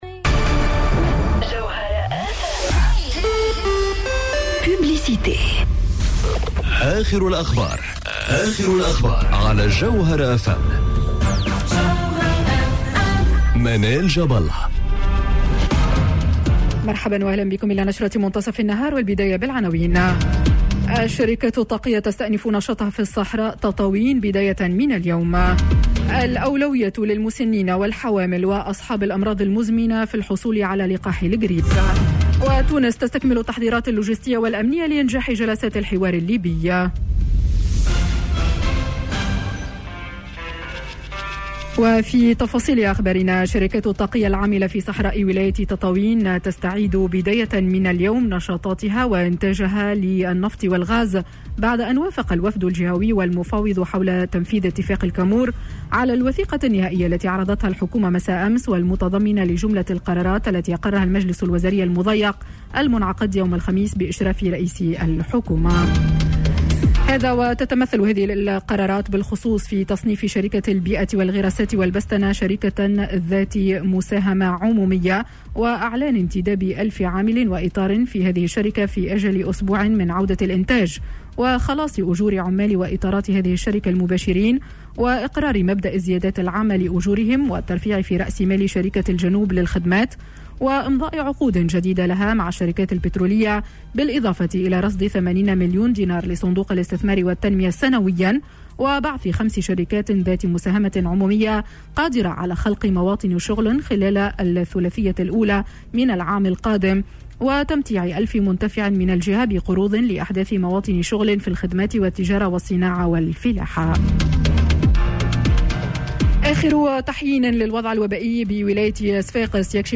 نشرة أخبار منتصف النهار ليوم الجمعة 07 نوفمبر 2020